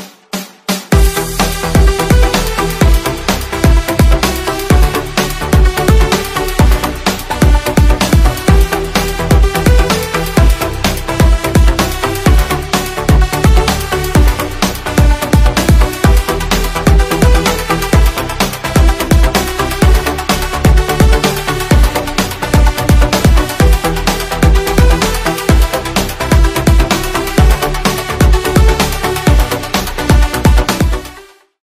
Ремикс
без слов